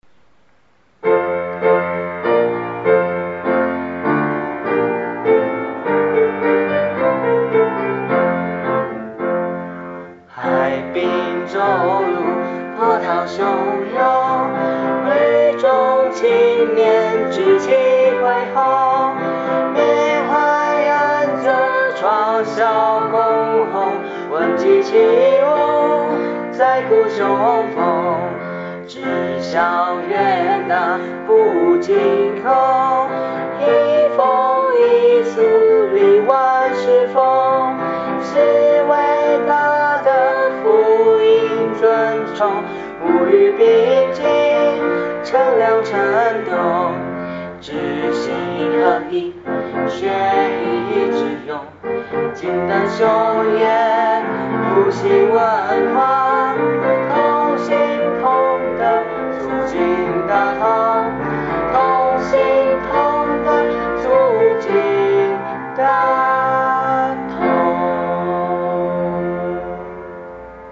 演唱檔
校歌演唱.mp3